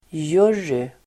Uttal: [j'ur:y]